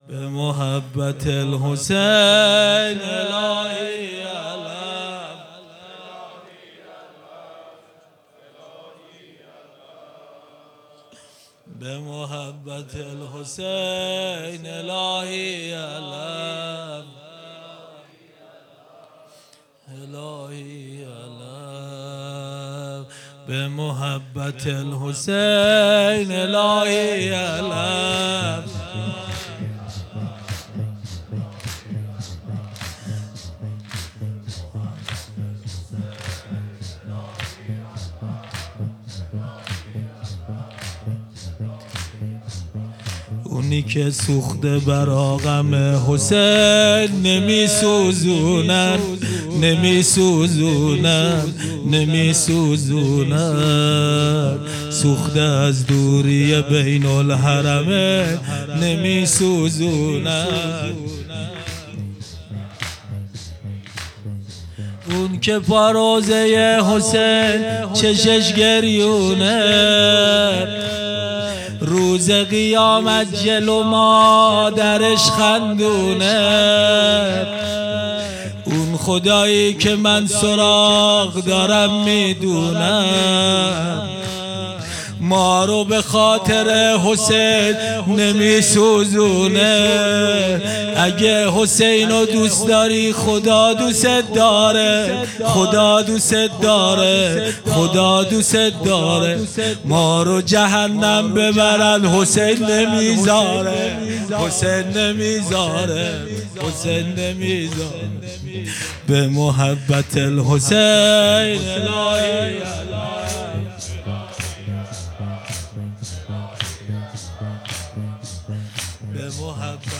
مــراسـم احیــاء شــب بیـــست و ســـوم مـاه مــبارک رمــضان ســال ۱۴۴۶